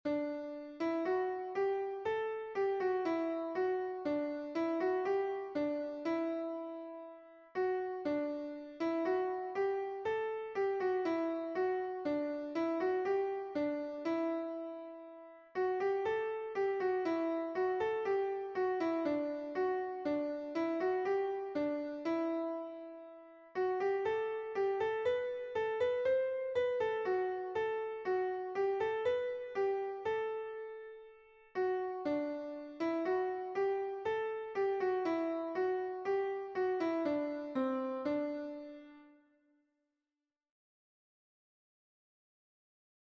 It's in D Mixolydian, 4/4 time.